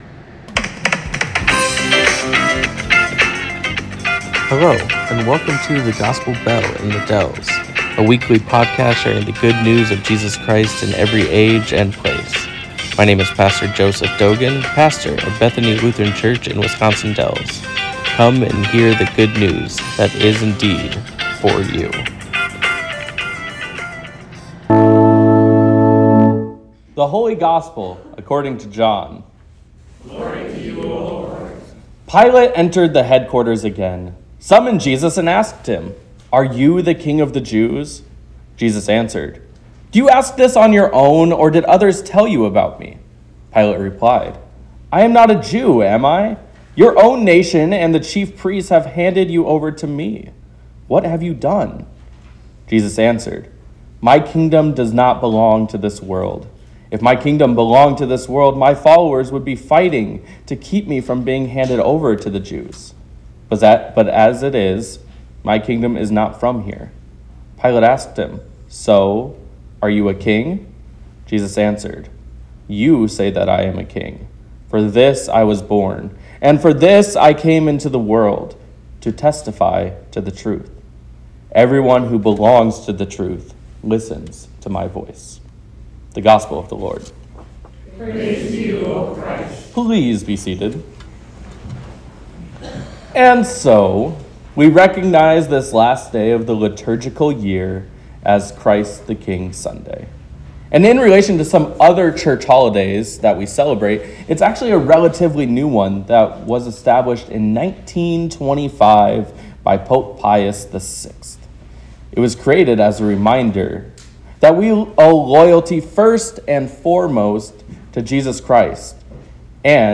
Christ The King Sermon